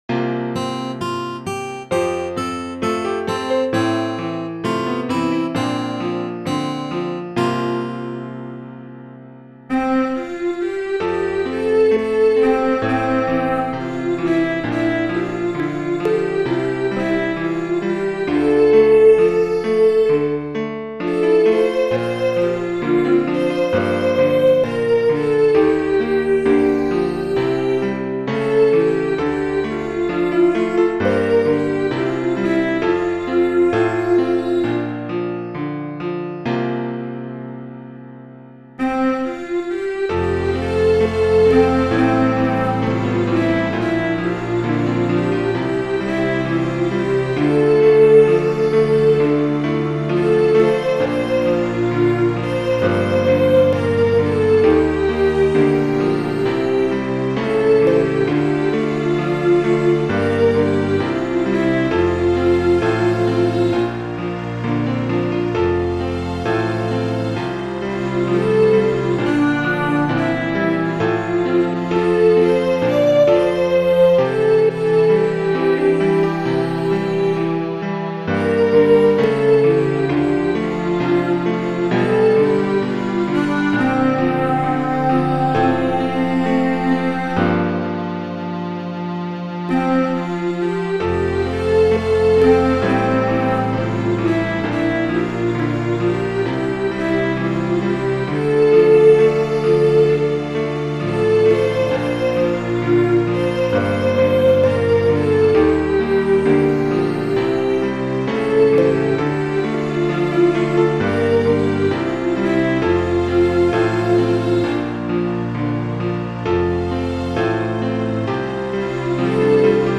This Advent song is certainly suitable for an assembly that can’t handle syncopation and that’s a rarity in this collection. Bob Hurd has written dignified and restrained hymn.
This fine choir aren’t always quite together but listen for the trumpet – it’s a blast.